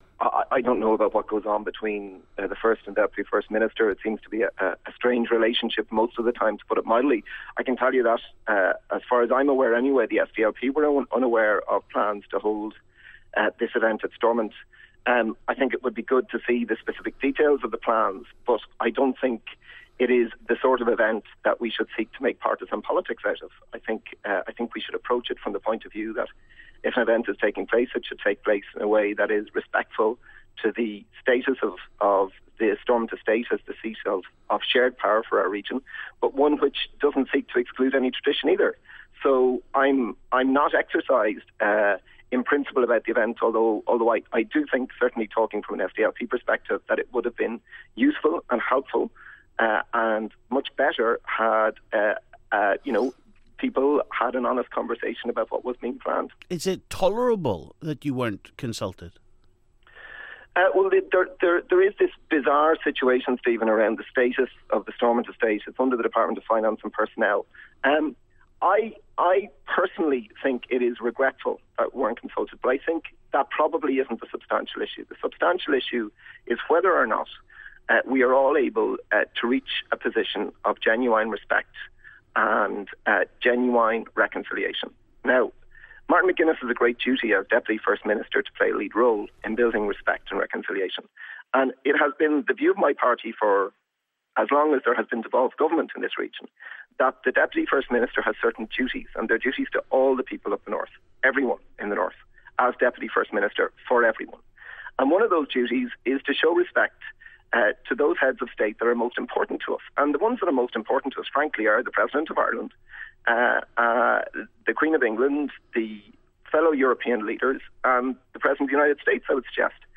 Sinn Fein says it wasn't consulted about a big Jubilee party planned for the grounds of Stormont later this month. We hear from SDLP's Conal McDevitt AND Danny Kinnahan from the UUP